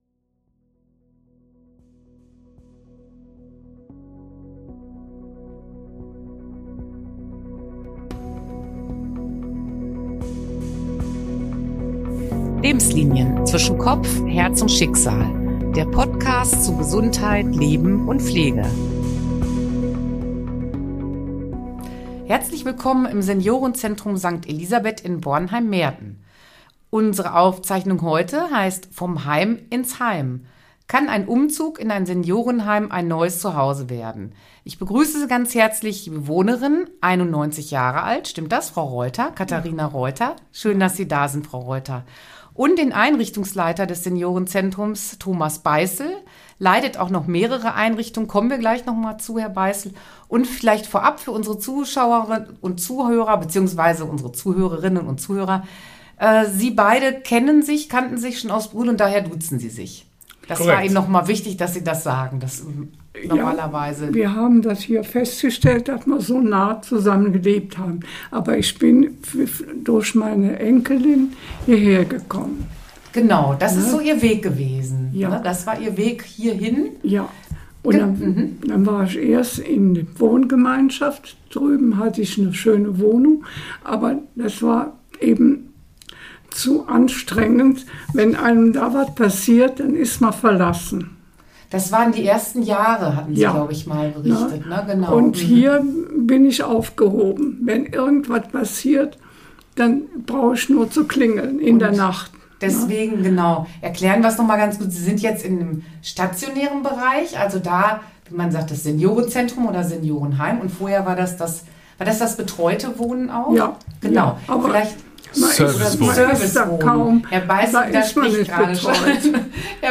Ein spannendes und offenherziges Gespräch über das Älterwerden, die Schwierigkeiten und auch Chancen, die der letzte Lebensabschnitt mit sich bringt.